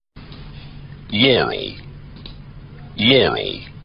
just listen to the pitch shifted version and you'll hear it, at least i did...